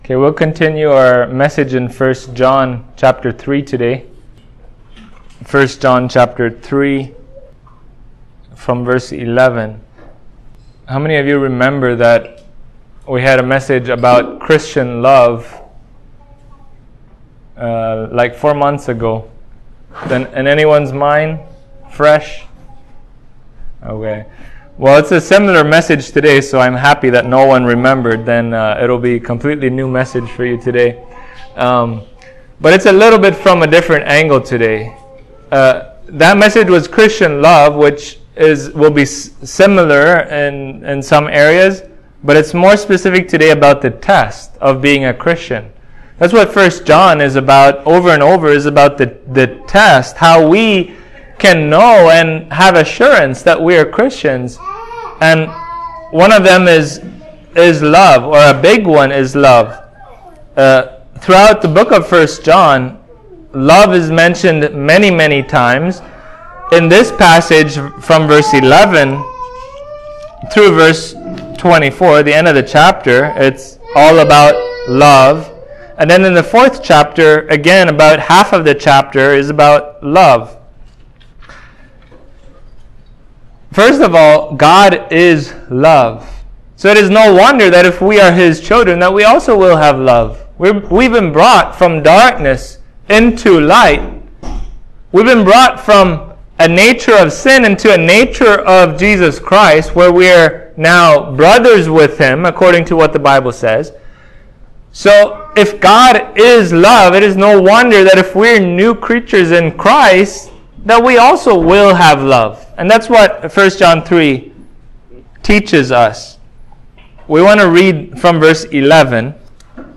1John 3:11-24 Service Type: Sunday Morning We know what love is because of God